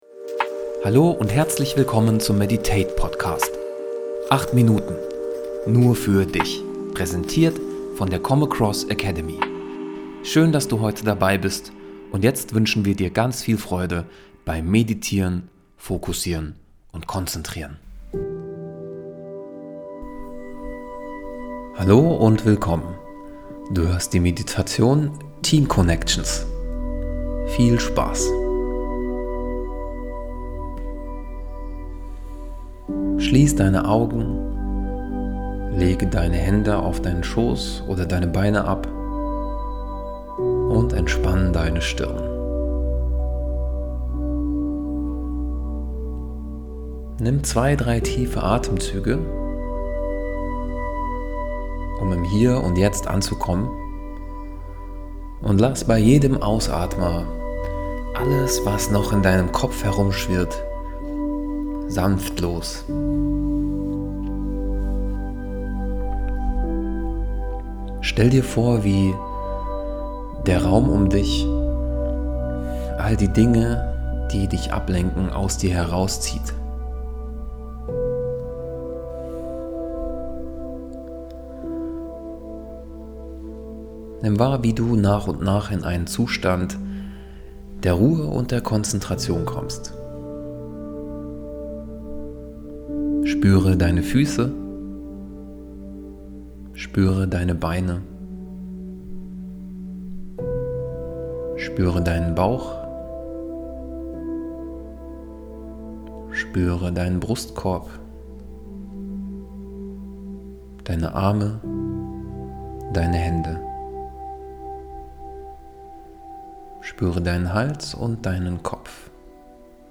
Teamconnections [Meditation]